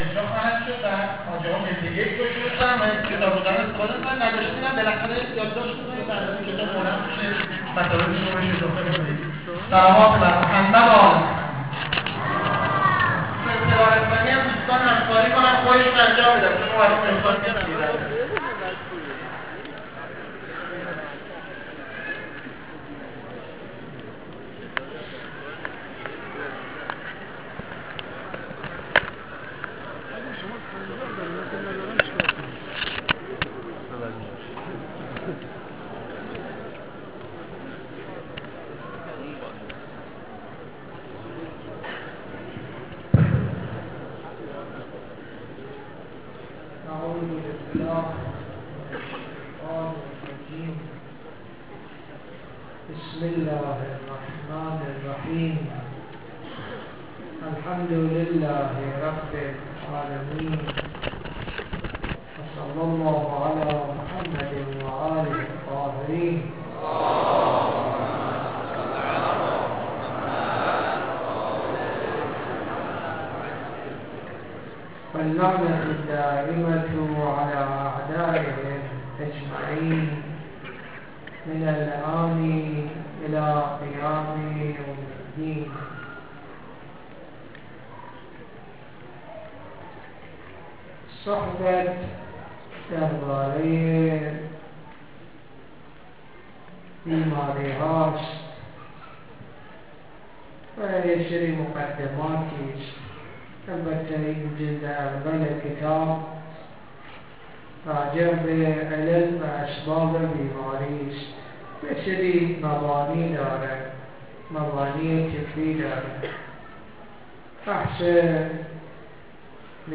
جلسه دوم ، مسجد پردیسان